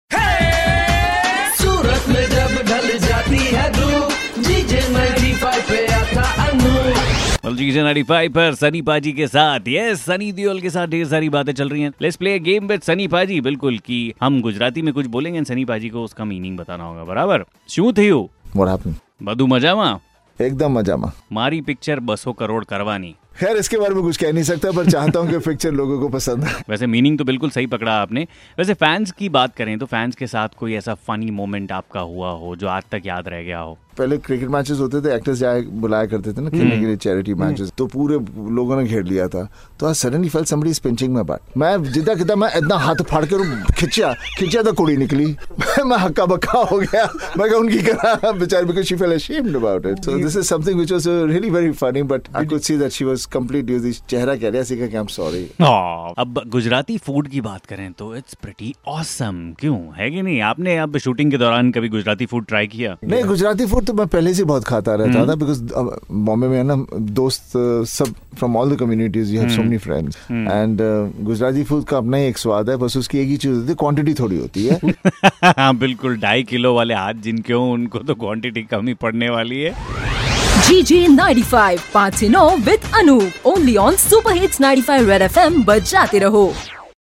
IN CONVERSATION WITH SUNNY DEOL